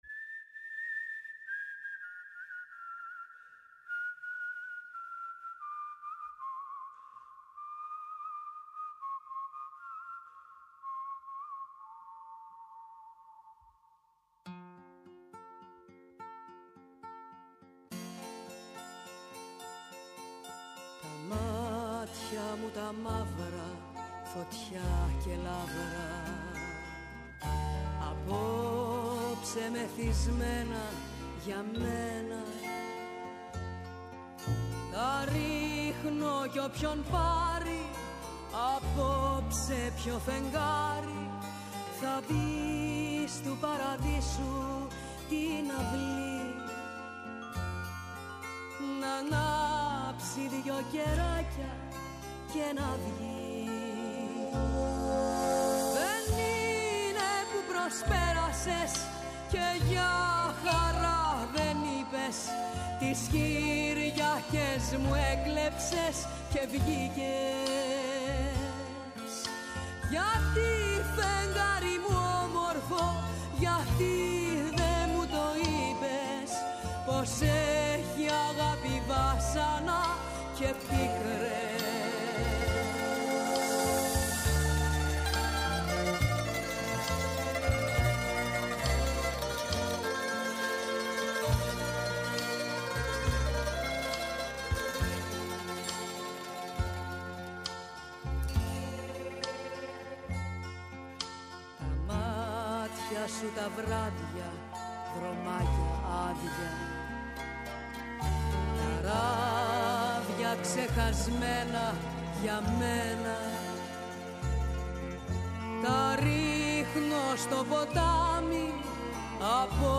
Θεωρείται μία από τις πιο καθαρόαιμες λαϊκές φωνές.